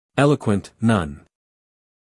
英音/ ˈeləkwənt / 美音/ ˈeləkwənt /